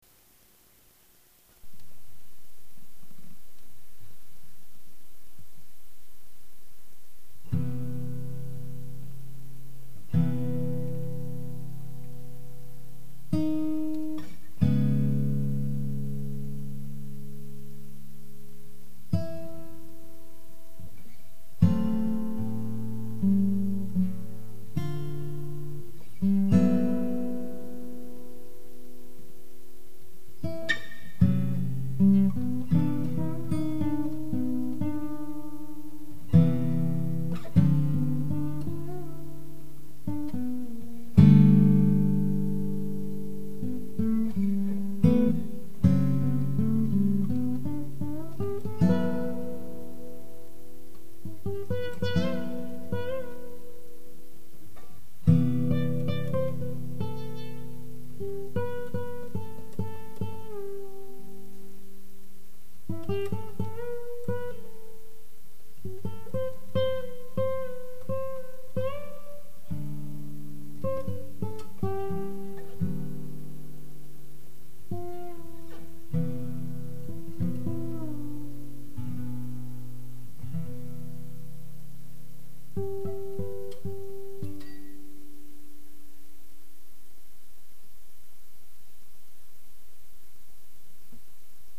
Duet for Guitar and Fretless Guitar